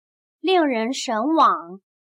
令人神往/lìng rén shénwǎng/Fascinante, lo que hace que la gente anhele mucho.